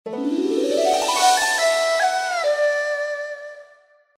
Message tone 08.mp3